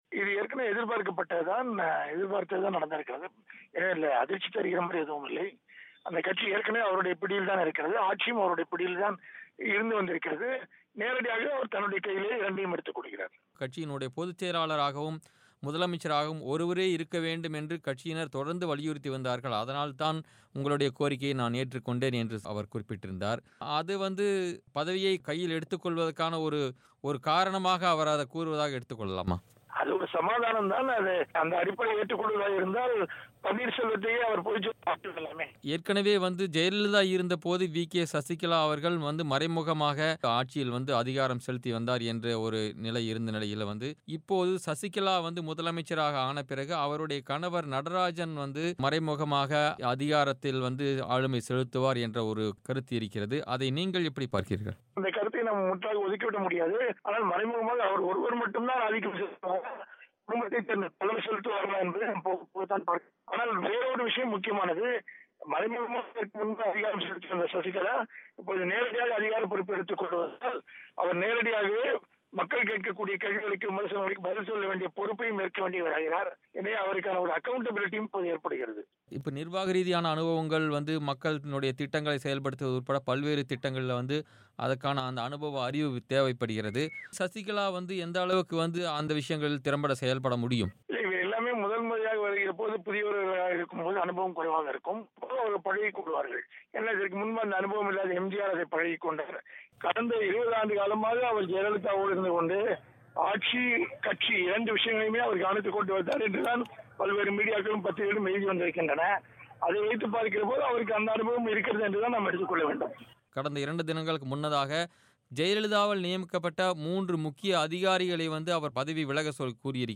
ஜெயலலிதாவுக்கு அளிக்கப்பட்ட சிகிச்சை குறித்த தகவல்களை பகிரங்கப்படுத்தி, தன் மீது குற்றமில்லை என நிரூபிக்க வேண்டிய கடமை சசிகலாவுக்கு உள்ளதாக அரசியல் விமர்சகர் ஞாநி, பிபிசி தமிழுக்கு பேட்டி.